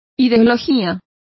Complete with pronunciation of the translation of ideology.